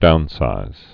(dounsīz)